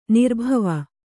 ♪ nirbhava